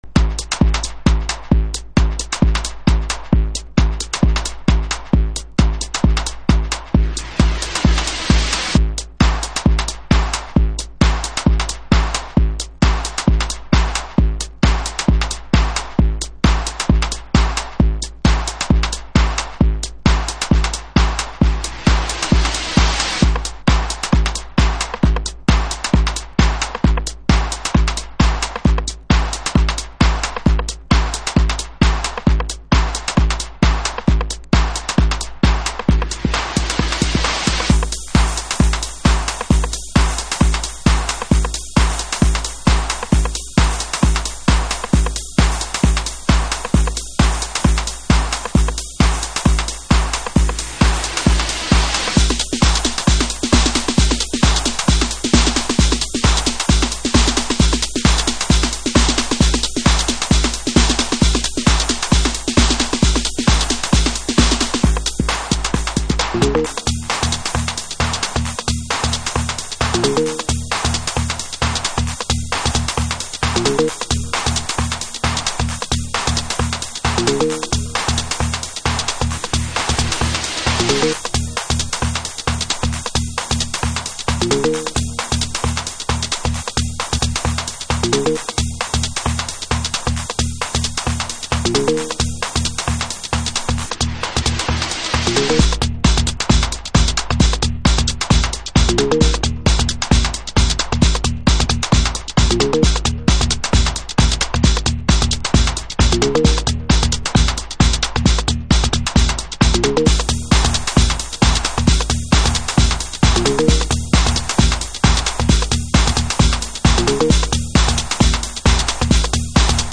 Early House / 90's Techno